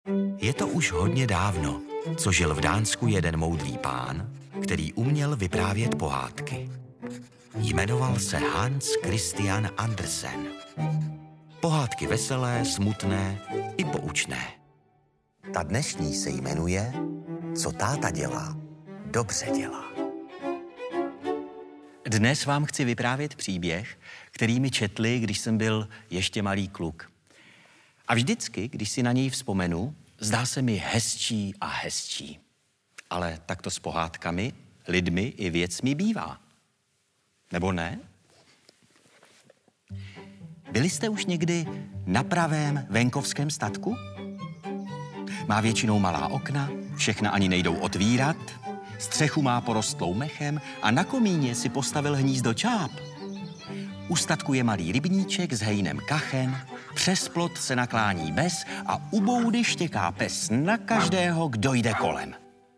Jeho zvučný hlas se objevuje také v mnoha audioknihách. Audiokniha Tajemný pás, autorka Božena Němcová, čte Jiří Dvořák.